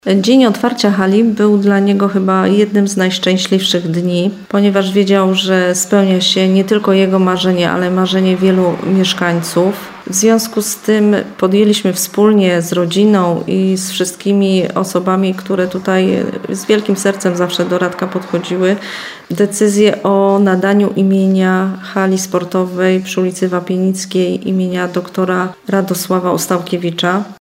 Budowa obiektu była jednym z najważniejszych przedsięwzięć pierwszej kadencji samorządowca - mówi obecna wójt Anna Skotnicka-Nędzka.